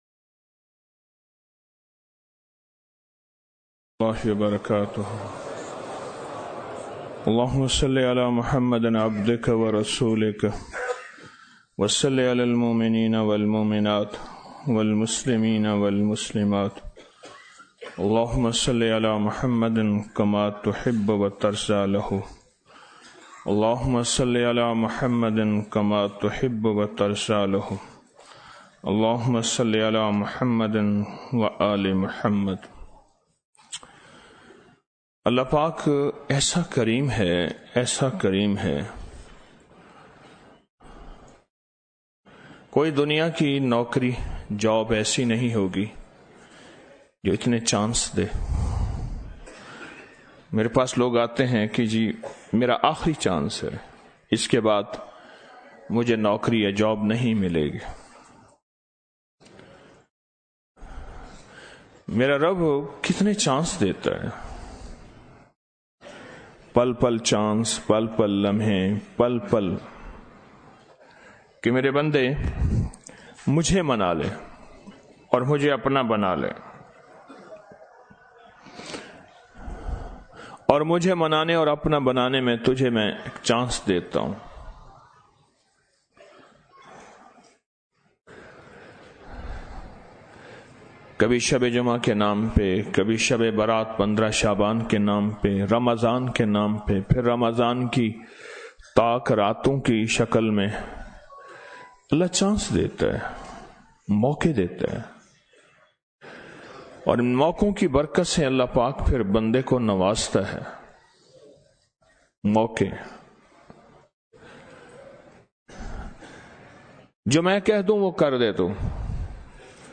شب برأت محفل - 25 فروری 2024ء